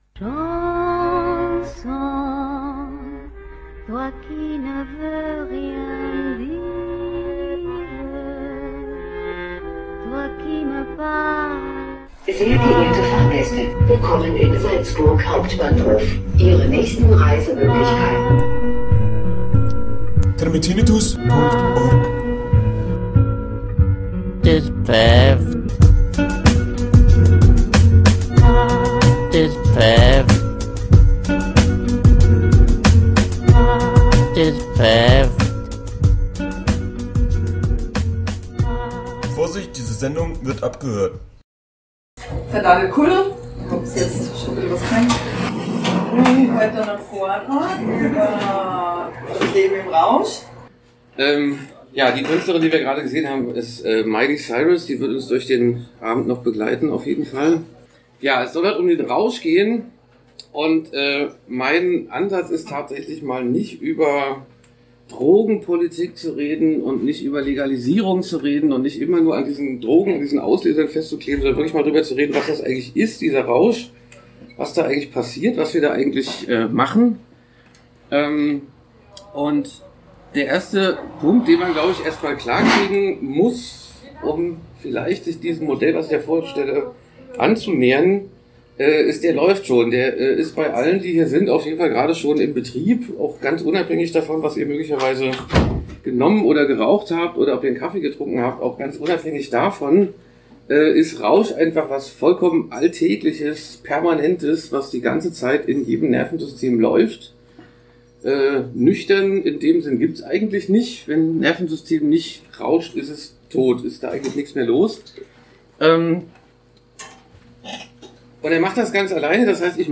Videos im Vortrag